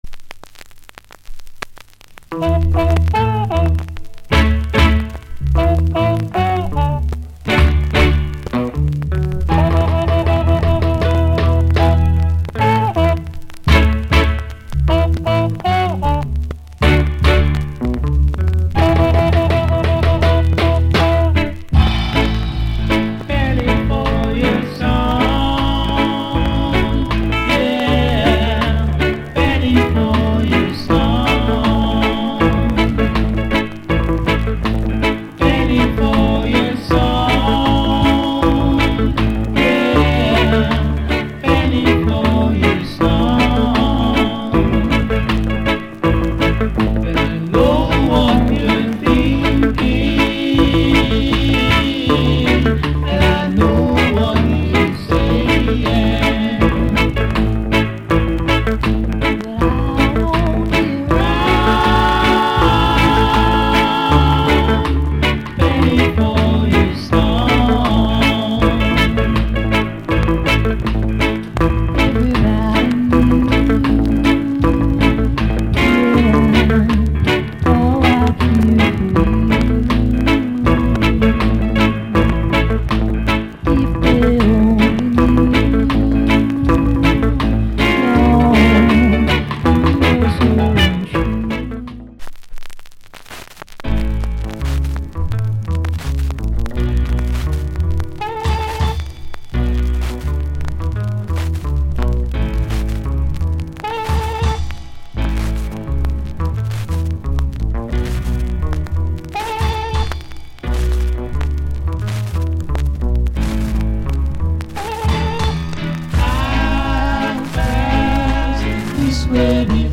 Genre Rock Steady / Male Vocal Group Vocal
** B面ヒートダメージによる白い曇りがあり、周期的なノイズあり。